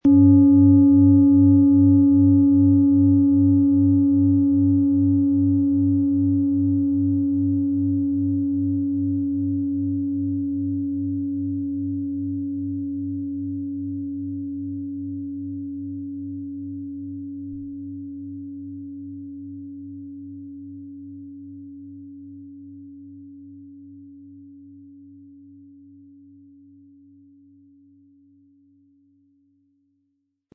Planetenschale® Stirb- und Werde-Prinzip & Neues beginnen mit Pluto, Ø 24 cm, 1200-1300 Gramm inkl. Klöppel
Planetenton 1
Im Sound-Player - Jetzt reinhören können Sie den Original-Ton genau dieser Schale anhören.
MaterialBronze